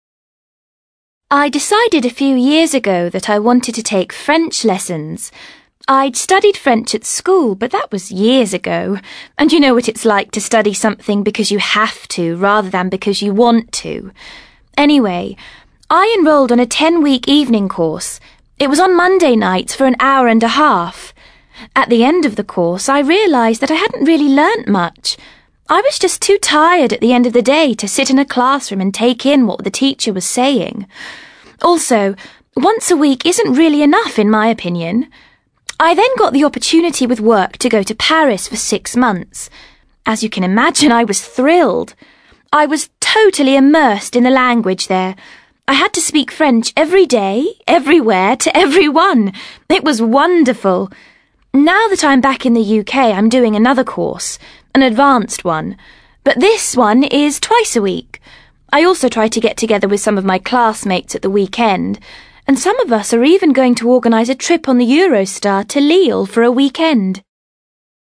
WOMAN